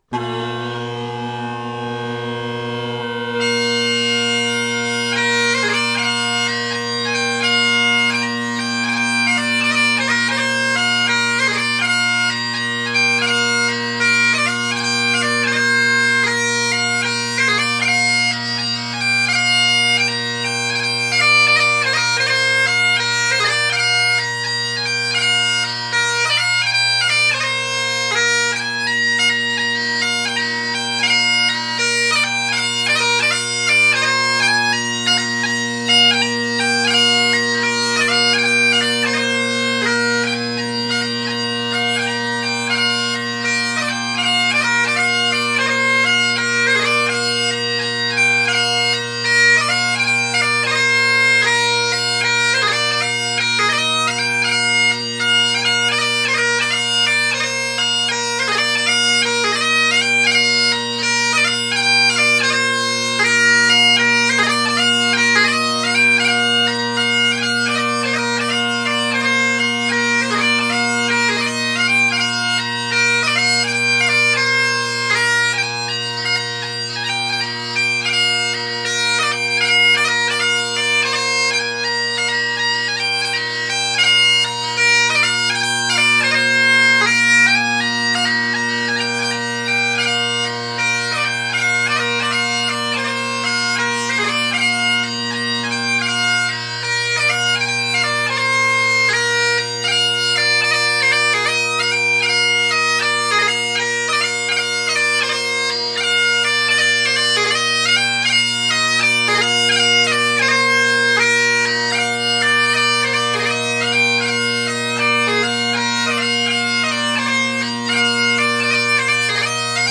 Great Highland Bagpipe Solo
Also, no one else was home so I got to play in a bigger room.
This is a poly Kron, #219, so it is one of the original Kron poly chanters before the modification around #600 which apparently fixes a potential flat F# (of which this one obtained used has the F carved).
:o) The E is just tad sharp, oops.